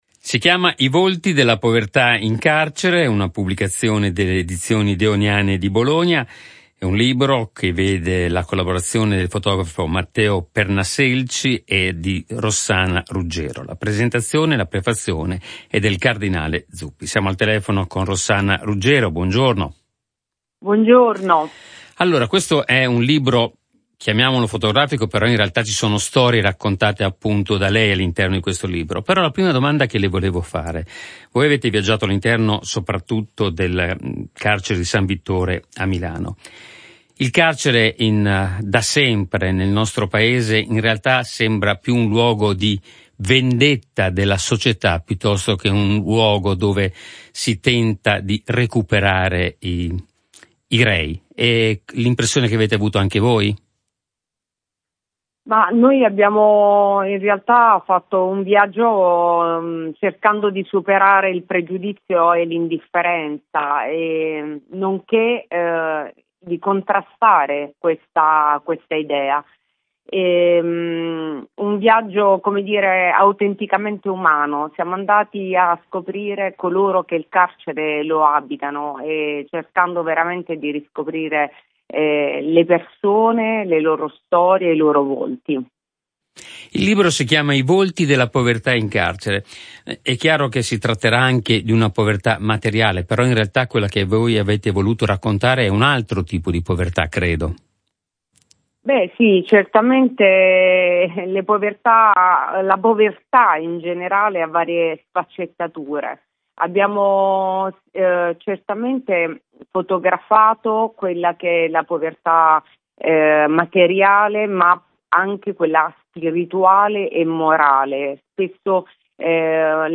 L’intervista